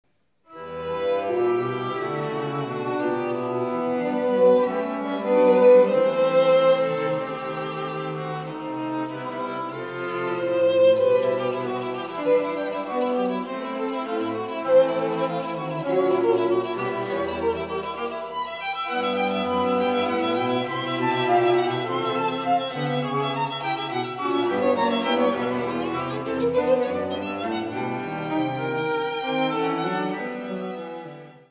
Neue Musik
Ensemblemusik
Quartett
Violine (2), Viola (1), Violoncello (1)